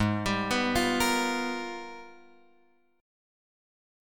G#mM13 chord {4 x 5 4 6 6} chord